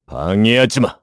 Clause_ice-Vox_Skill3_kr.wav